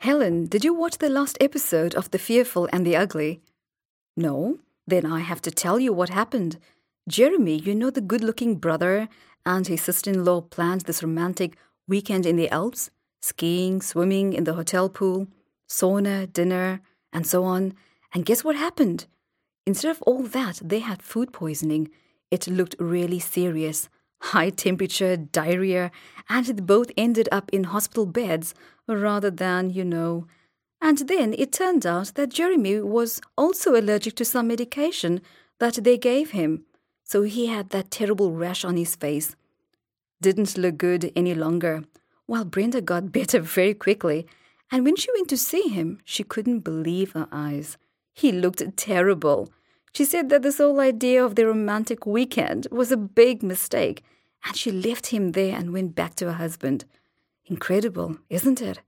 Listen to Mrs. Williams talking to her neighbour on the phone and answer the questions.